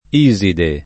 vai all'elenco alfabetico delle voci ingrandisci il carattere 100% rimpicciolisci il carattere stampa invia tramite posta elettronica codividi su Facebook Iside [ &@ ide ] pers. f. — come nome della dea egiziana, poet.